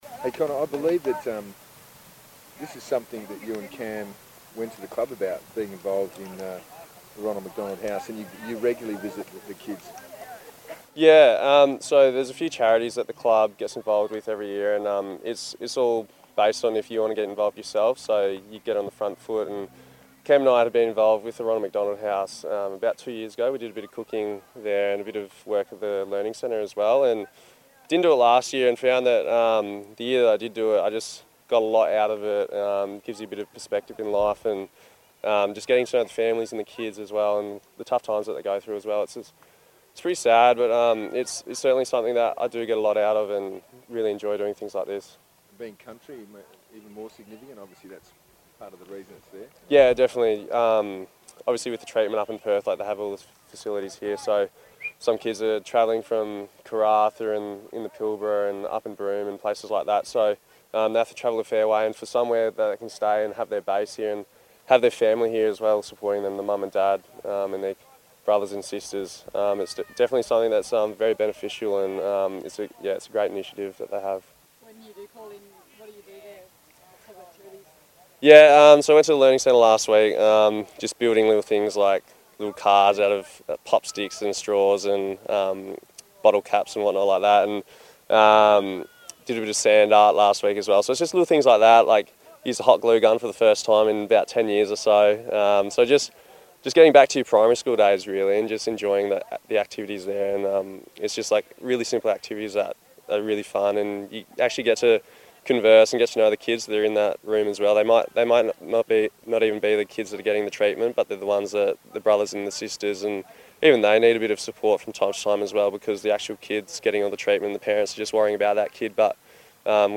Connor Blakely press conference - 18 April
Connor Blakely chats to media at a clinic for Ronald McDonald house.